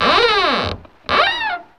Index of /90_sSampleCDs/E-MU Producer Series Vol. 3 – Hollywood Sound Effects/Human & Animal/WoodscrewSqueaks
WOOD SQUEA06.wav